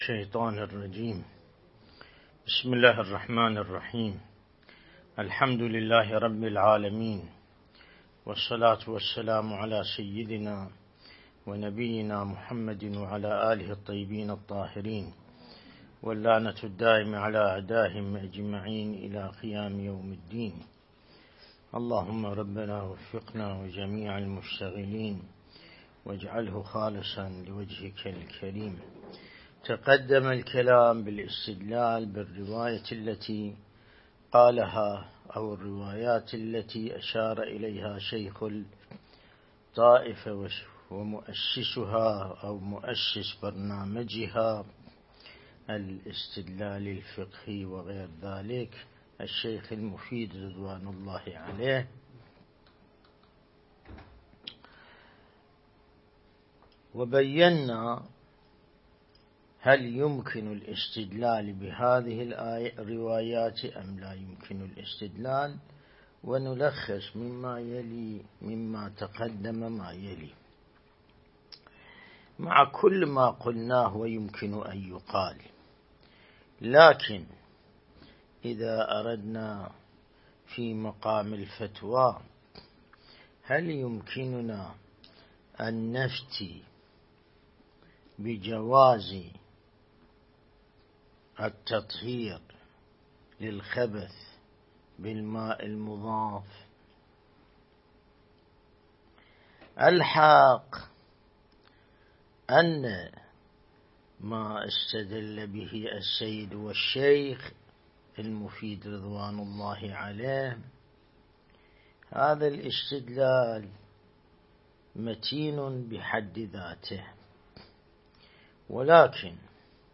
الدرس الاستدلالي شرح بحث الطهارة من كتاب العروة الوثقى لسماحة آية الله السيد ياسين الموسوي(دام ظله)